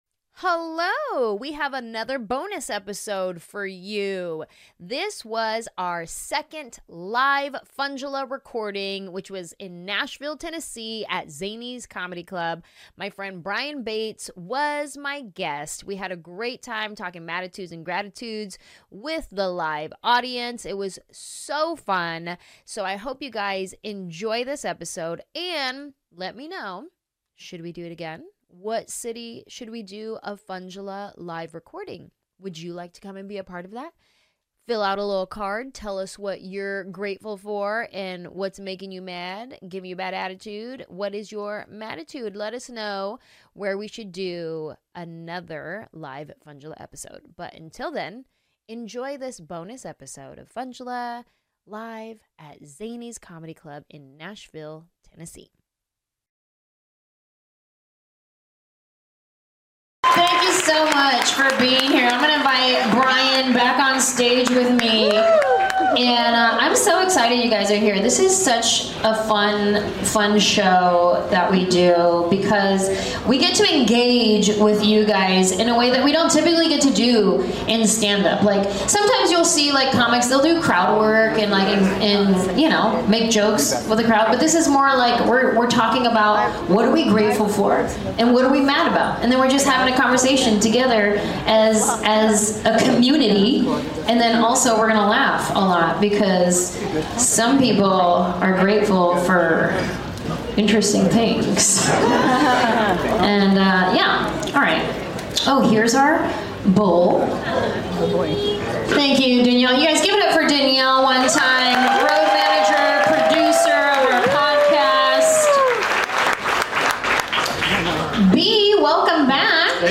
We did a live show!